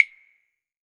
PCLAVE.wav